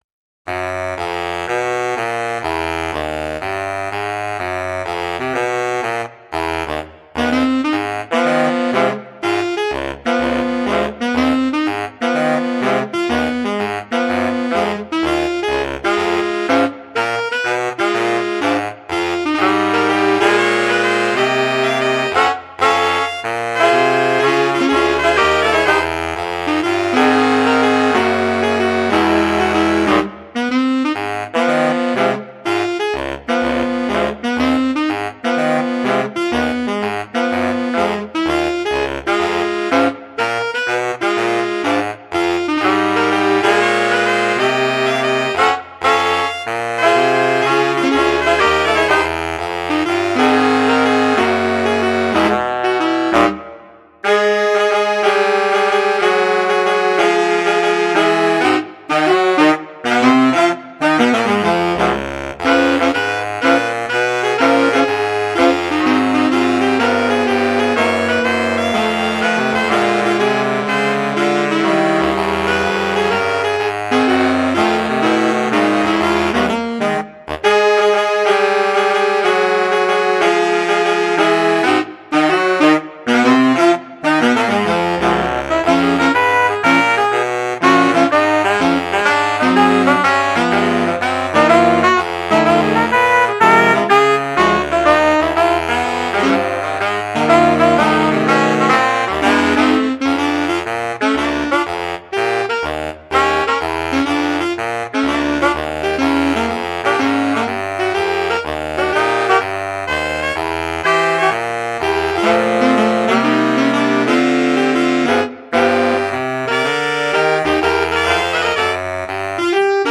Ranges: Alto 1: Eb3. Tenor: C#3. Baritone: A1 with ossias